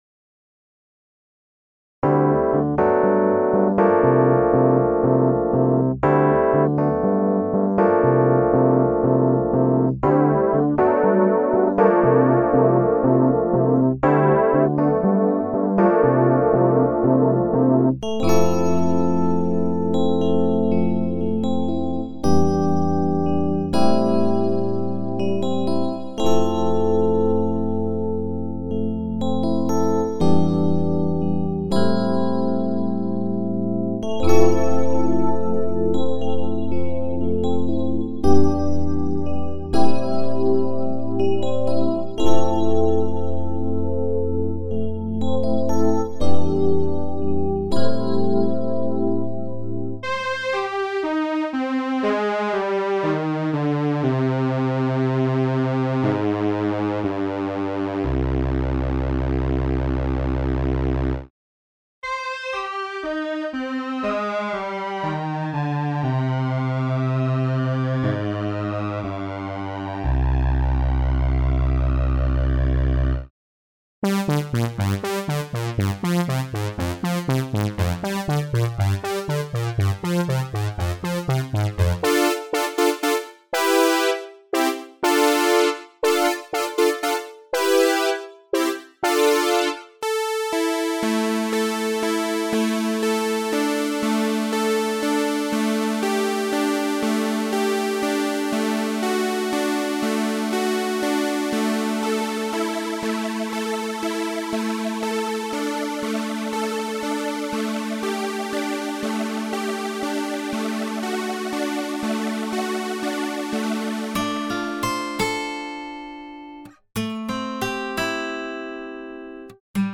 A Chorus with personality and full of vintage flavour.